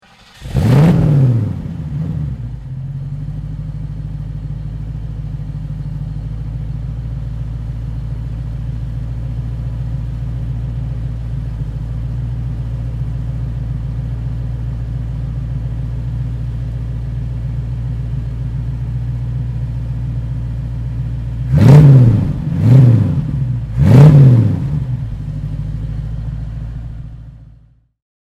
Ford Mustang (1965) - Starten und Leerlaufgeräusch